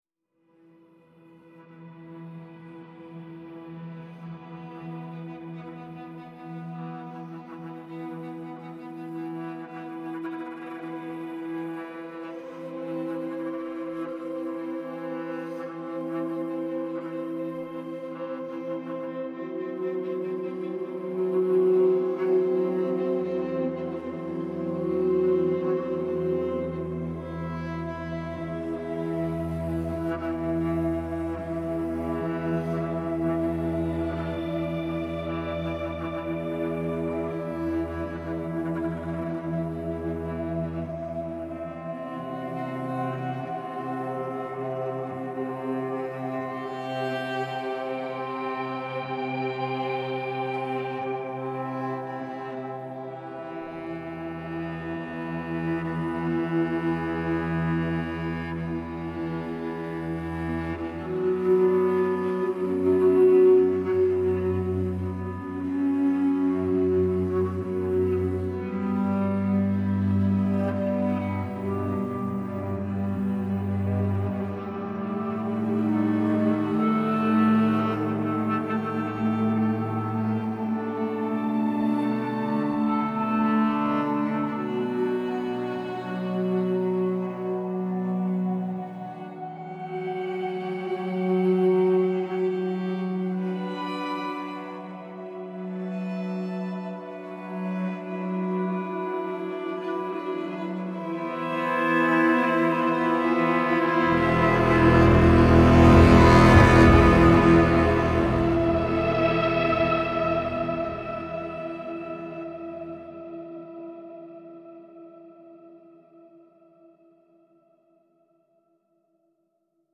(Drama)